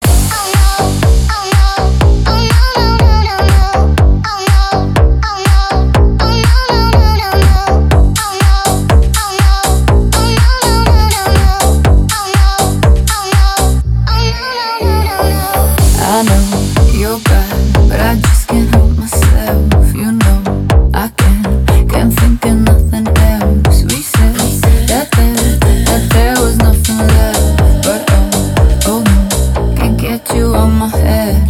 • Качество: 320, Stereo
громкие
женский голос
Club House